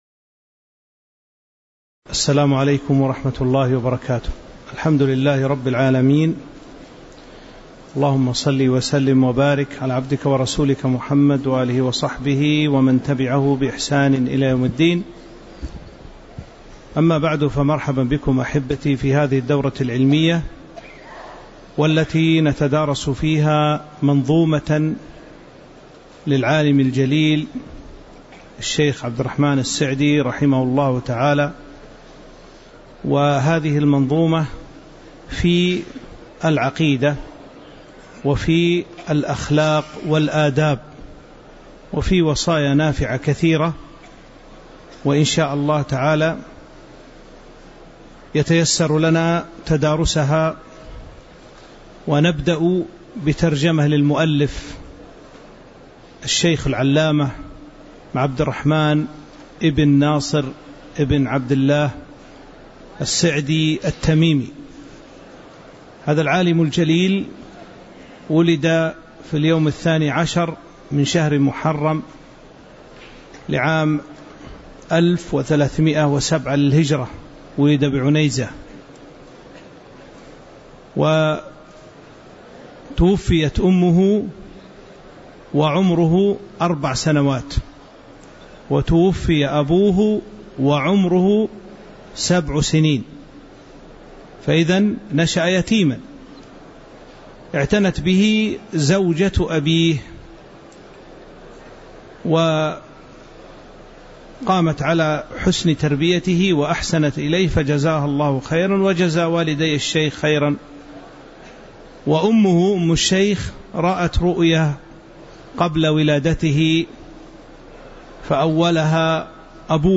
تاريخ النشر ١١ محرم ١٤٤٦ هـ المكان: المسجد النبوي الشيخ